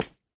Minecraft Version Minecraft Version snapshot Latest Release | Latest Snapshot snapshot / assets / minecraft / sounds / block / candle / step2.ogg Compare With Compare With Latest Release | Latest Snapshot